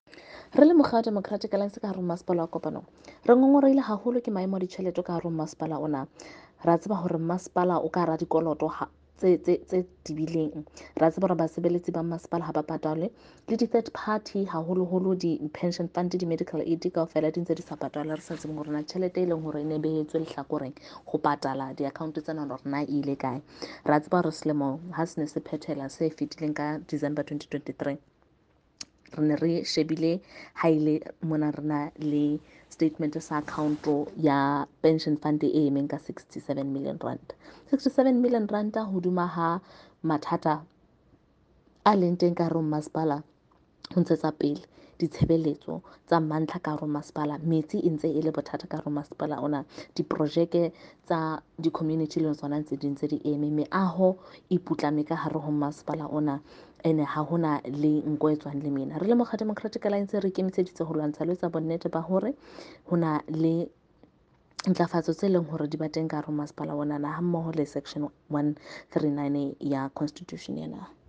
Sesotho by Karabo Khakhau MP.
Sotho-voice-Karabo.mp3